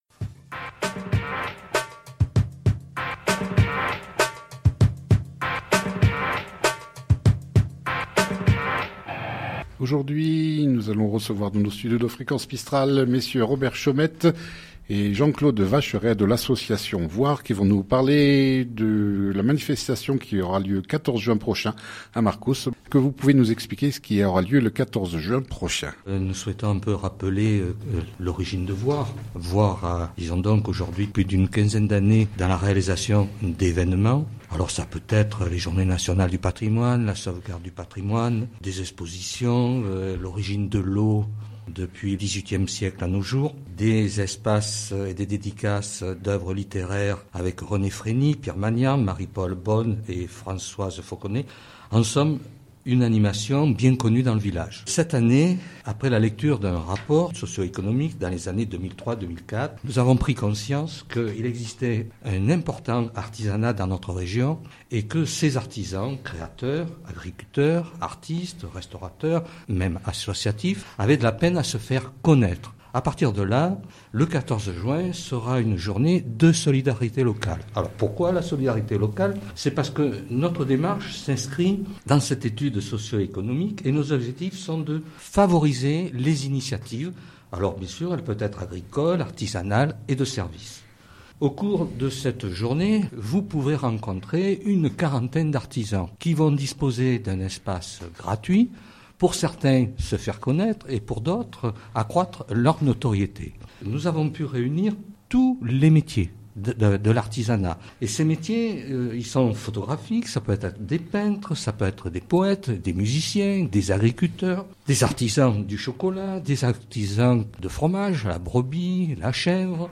Nous avons reçu dans notre studio de fréquence mistral à Digne-les-Bains